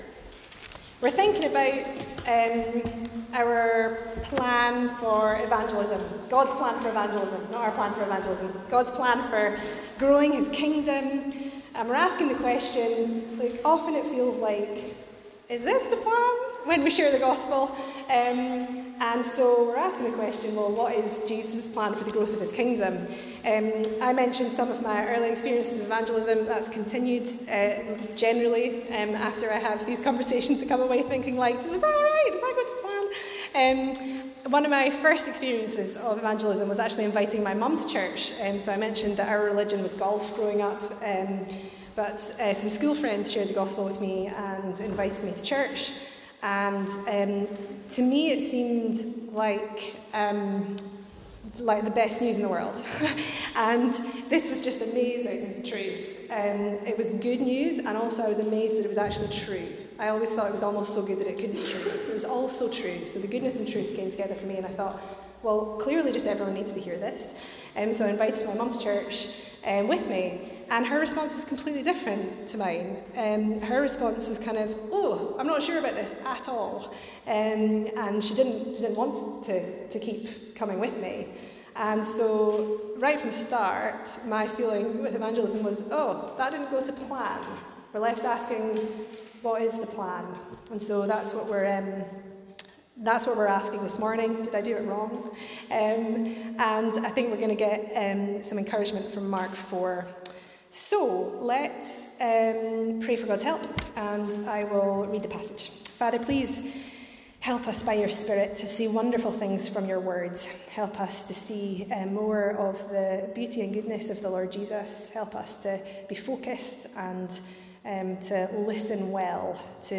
Sermons | St Andrews Free Church
Guest Speaker